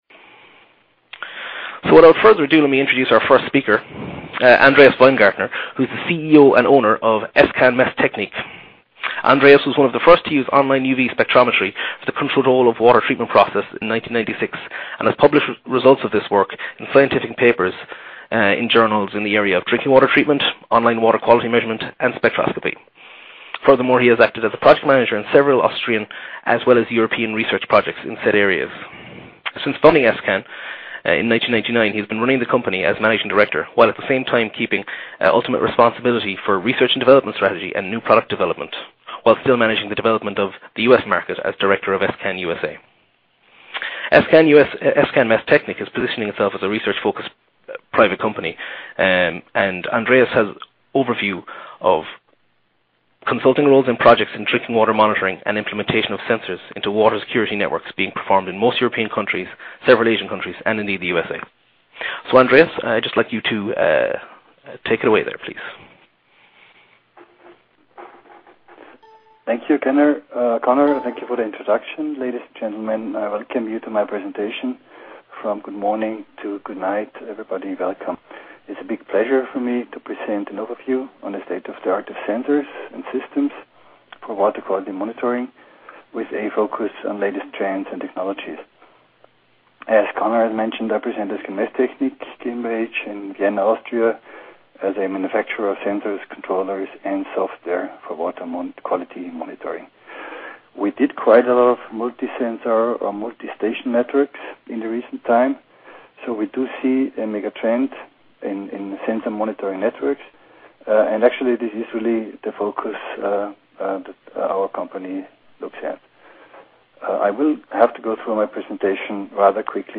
BlueTech Webinar_Online Sensing